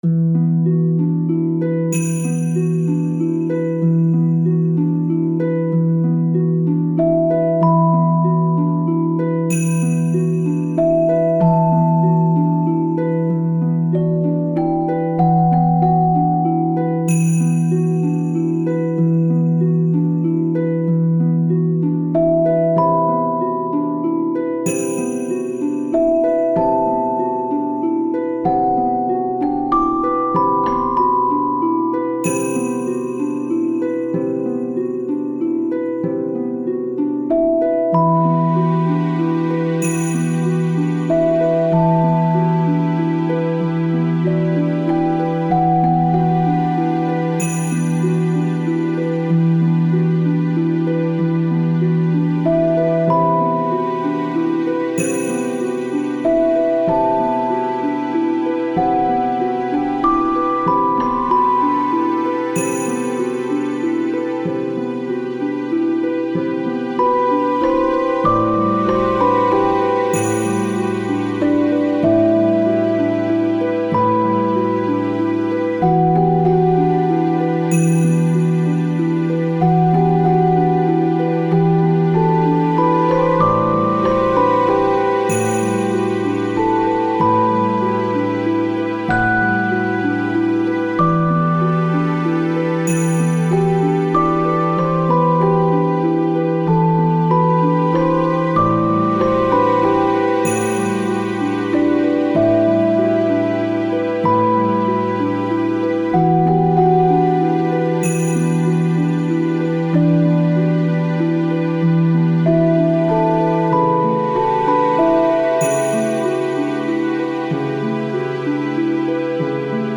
ハープ、チェレスタ、シンセストリングスの
静かで切ない神秘的な曲です